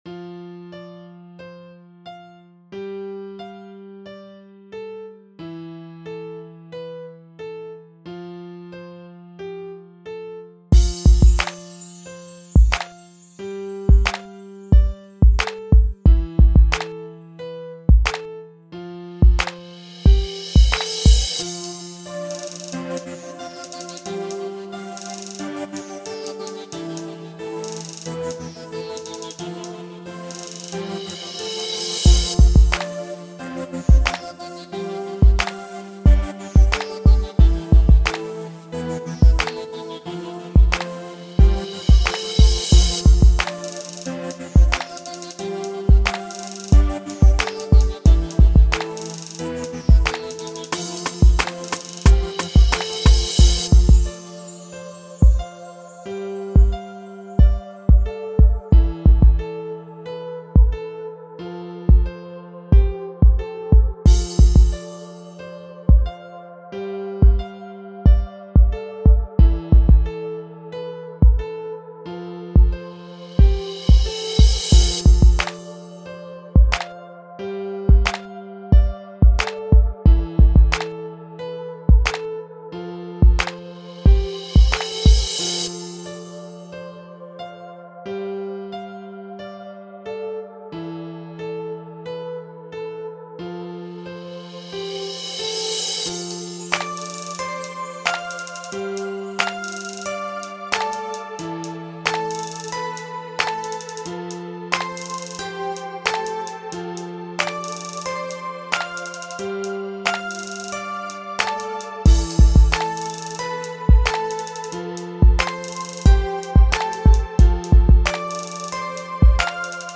Home > Music > Beats > Bright > Smooth > Medium